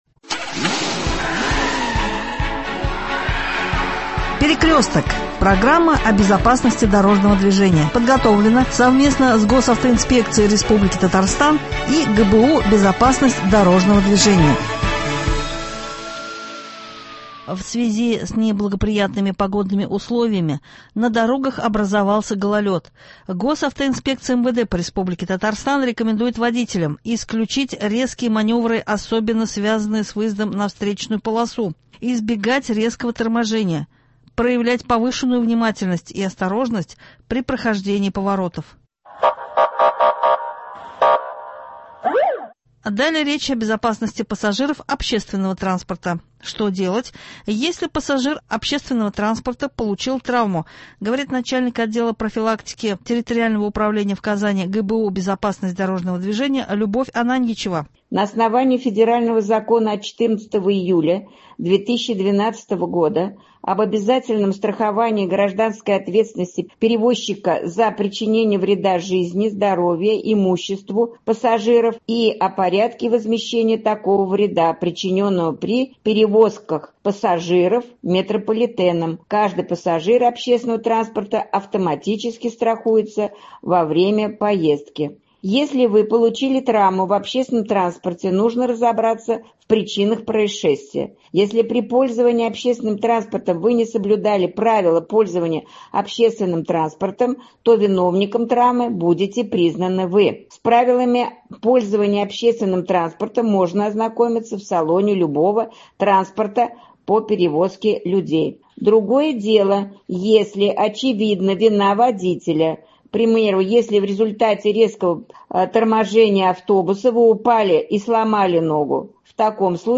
Важные советы –звучит фрагмент записи Всероссийского родительского собрании по профилактике детского дорожно-транспортного травматизма.